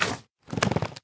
minecraft / sounds / mob / magmacube / jump3.ogg
jump3.ogg